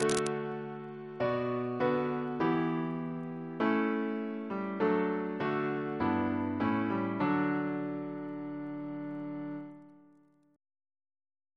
Single chant in D Composer: F. A. Gore Ouseley (1825-1889) Reference psalters: ACB: 36; CWP: 219; H1940: 677; H1982: S198; OCB: 246; PP/SNCB: 46; RSCM: 203